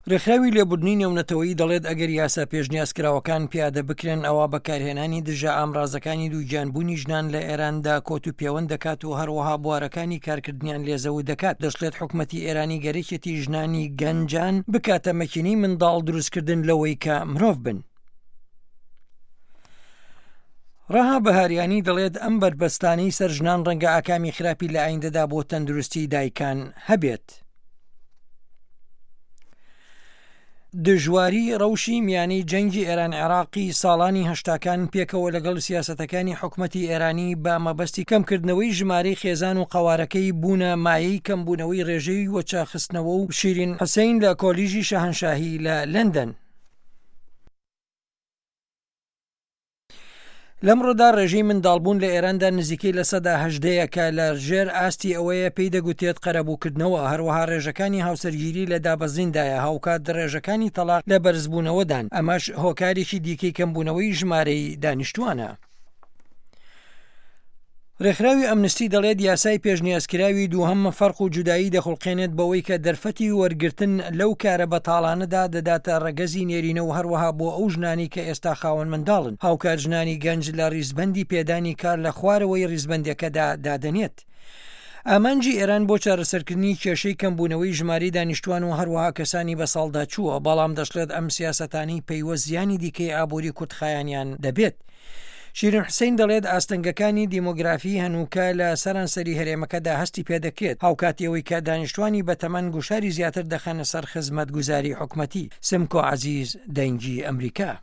راپۆرتی ئه‌مه‌ریکا و سوریا